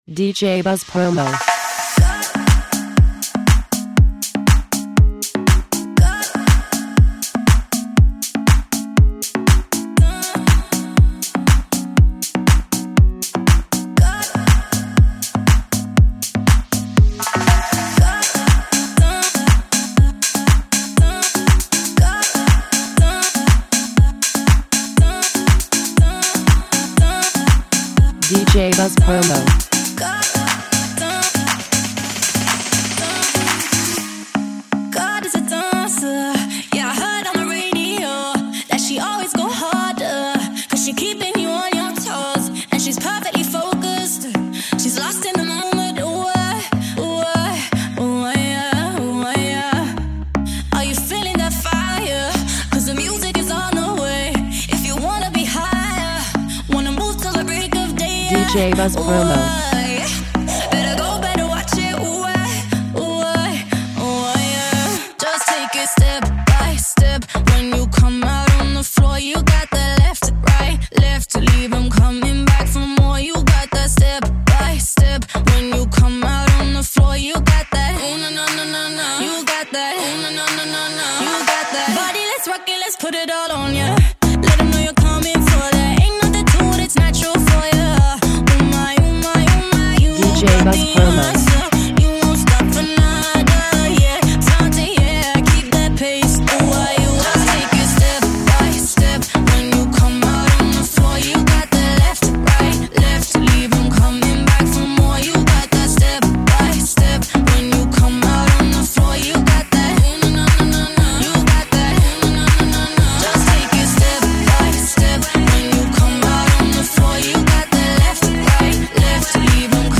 British Pop Star